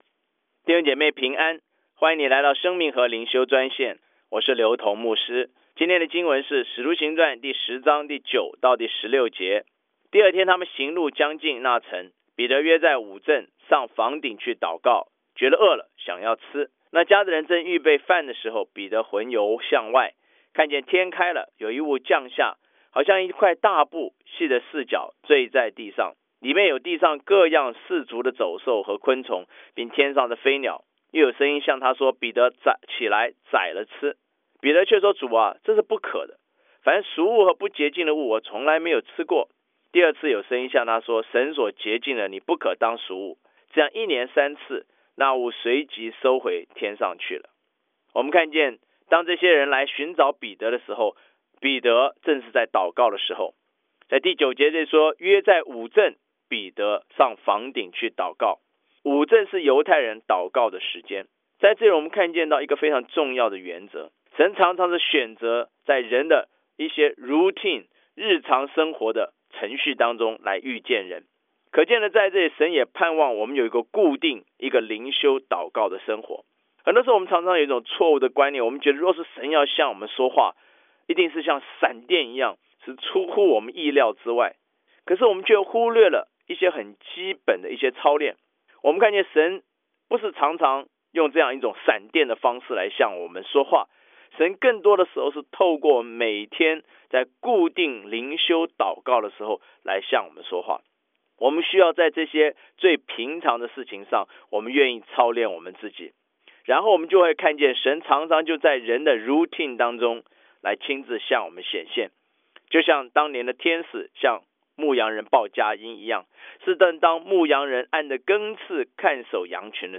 以生活化的口吻带领信徒逐章逐节读经